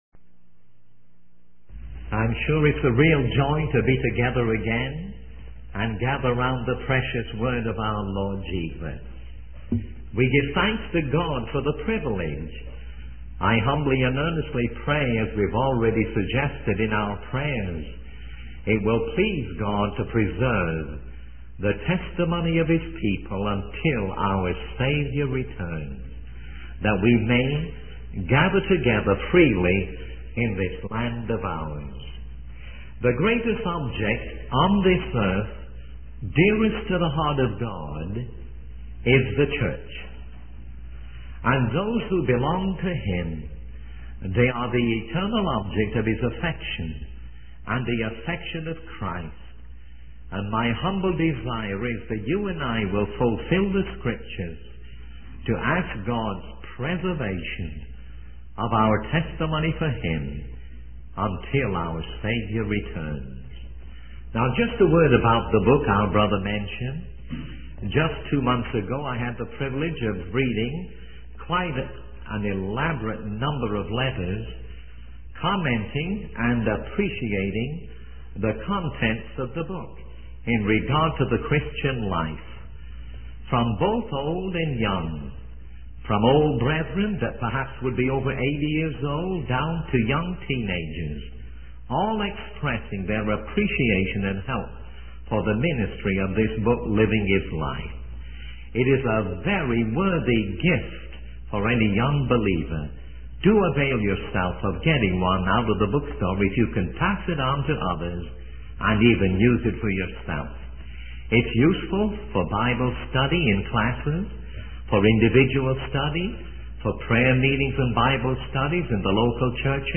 In this sermon, the speaker emphasizes the importance of believers devoting themselves to the will of God. He quotes Romans 12:1-2, which urges believers to present their bodies as living sacrifices and to be transformed by the renewing of their minds.